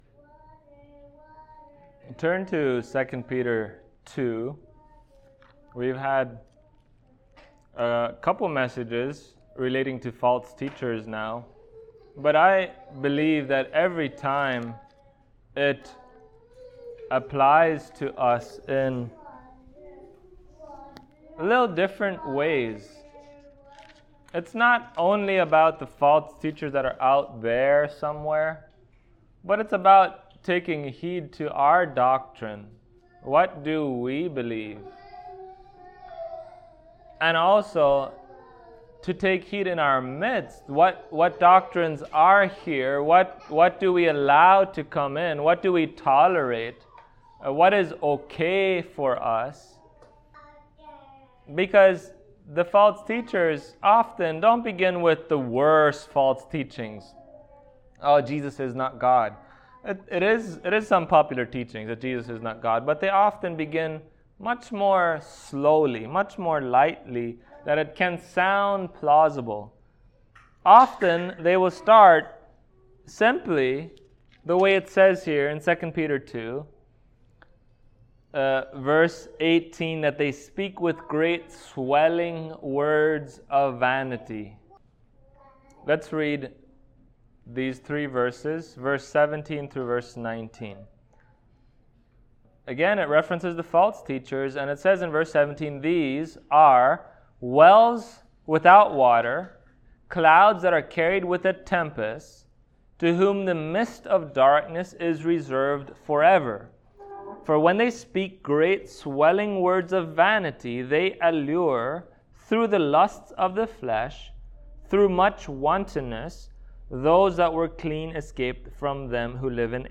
Passage: 2 Peter 2:17-19 Service Type: Sunday Morning